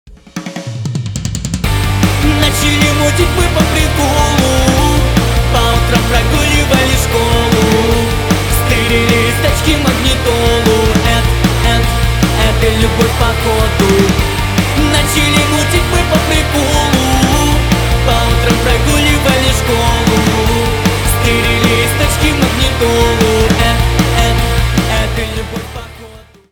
• Качество: 320 kbps, Stereo
Рок Металл
весёлые